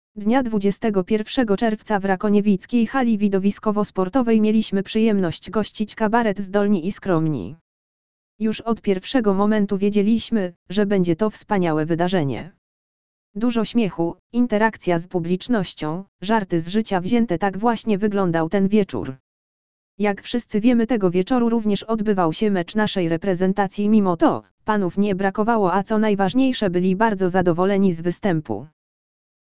Dnia 21 czerwca w rakoniewickiej hali widowiskowo-sportowej mieliśmy przyjemność gościć Kabaret Zdolni i Skromni.
Dużo śmiechu, interakcja z publicznością, żarty z życia wzięte – tak właśnie wyglądał ten wieczór.
dnia_21_czerwca_w_rakoniewickiej_hali_widowiskowosportowej.mp3